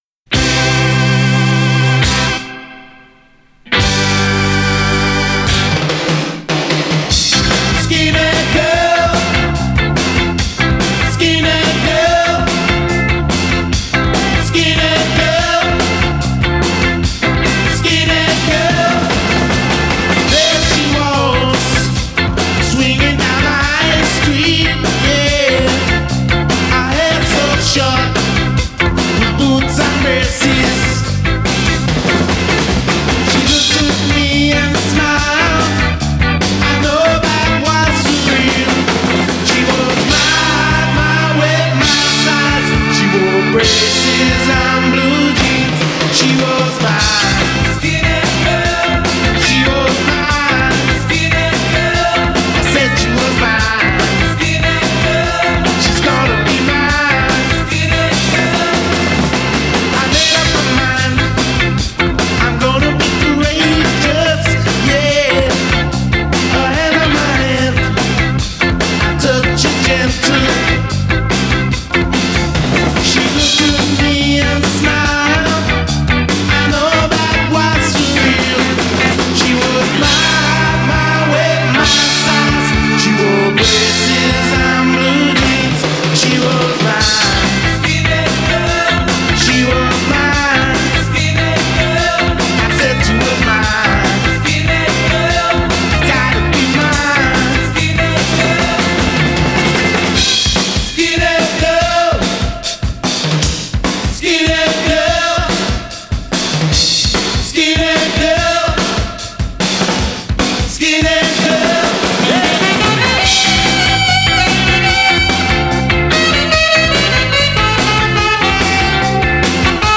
Genre: Ska, Two-Tone, New Wave, Reggae